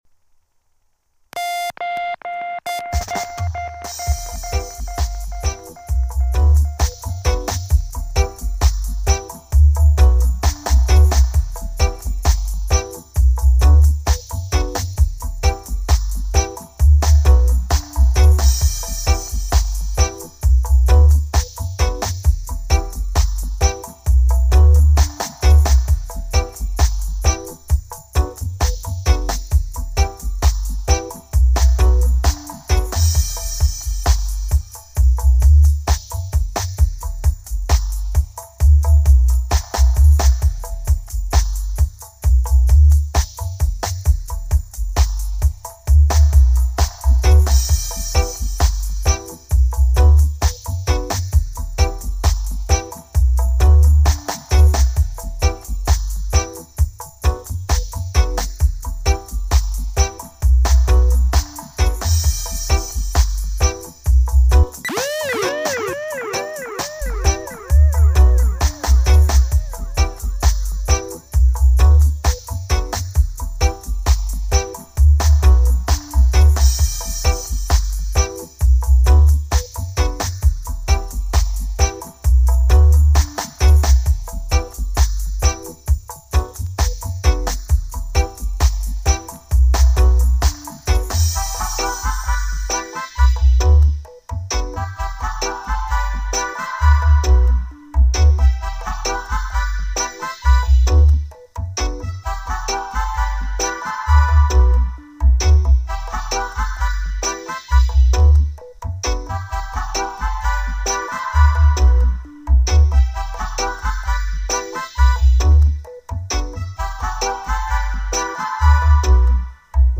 Regular Sunday Show 2.30 to 4pm (UK Times) - Rootikal sounds and good vibes!